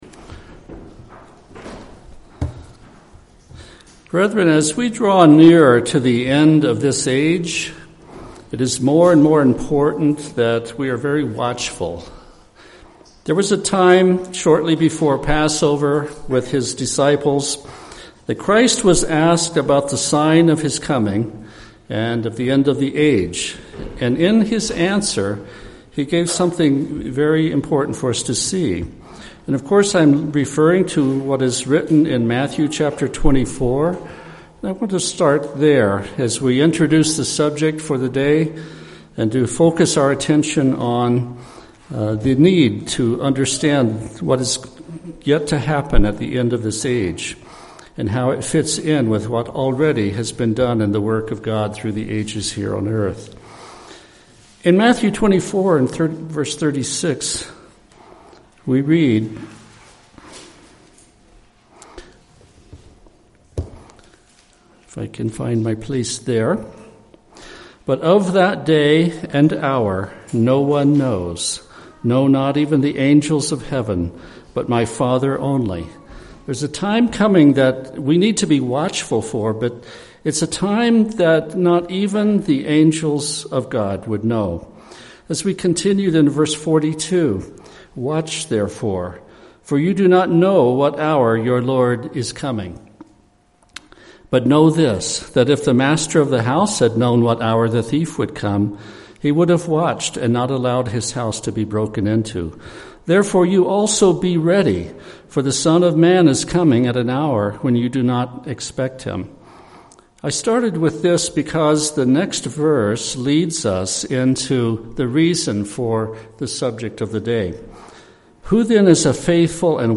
This sermon offers "food in due season" on the topic of Christ's role in three great epic events of redemption in God's plan to save the world. This message will look at the time of Israel came out of Egypt, at the period that includes the ministry and sacrifice of Jesus Christ, and of the yet future time of redemption that will come at the end of this age.